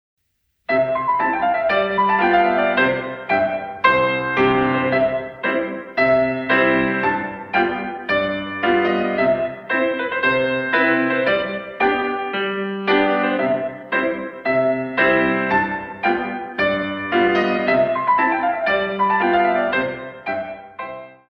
Pianist
In 2